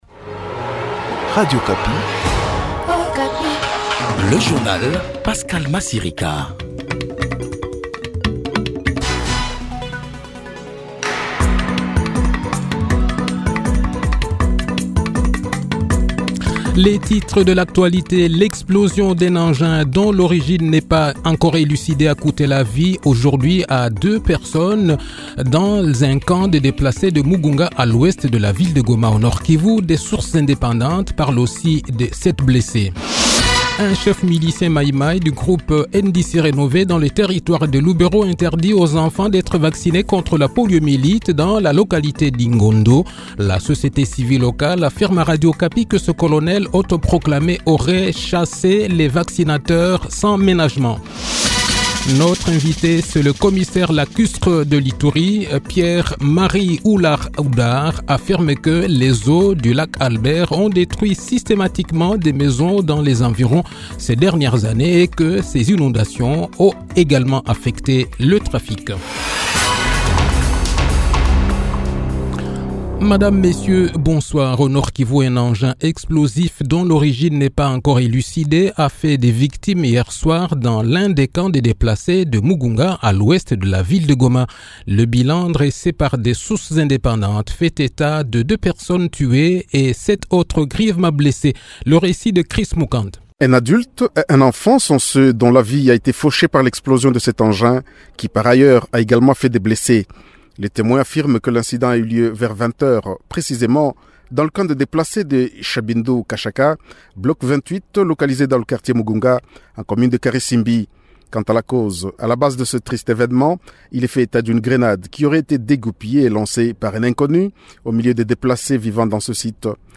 Le journal de 18 h, 5 avril 2024